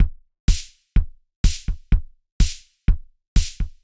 IBI Beat - Mix 6.wav